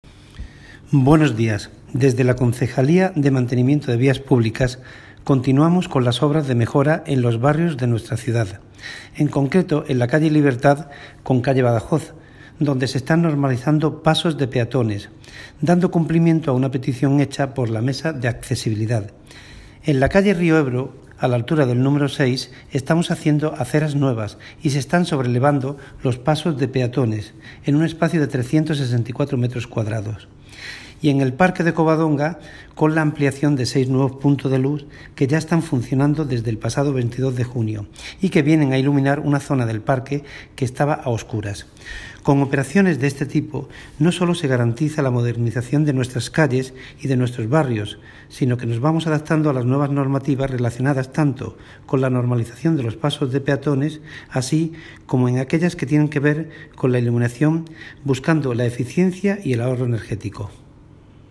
Audio - Agustín Martín (Concejal de Deportes , Medio Ambiente, Obras y Mantenimiento de Vías Públicas) Sobre Obras